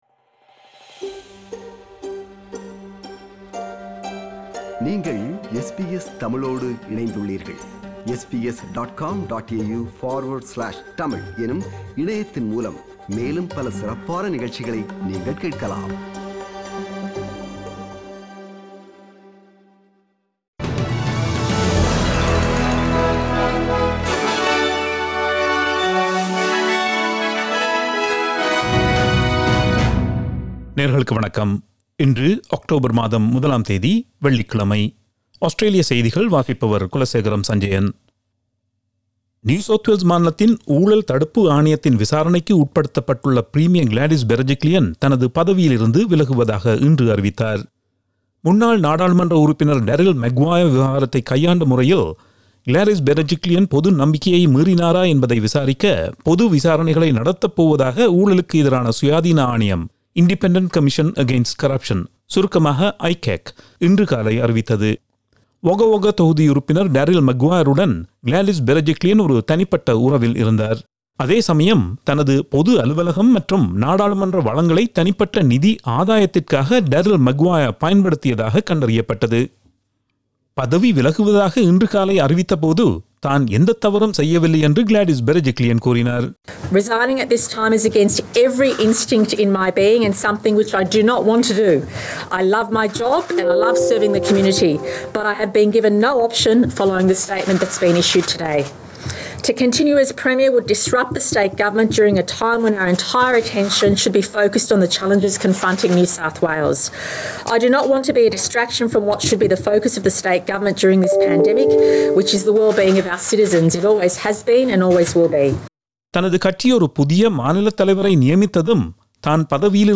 Australian news bulletin for Friday 01 October 2021.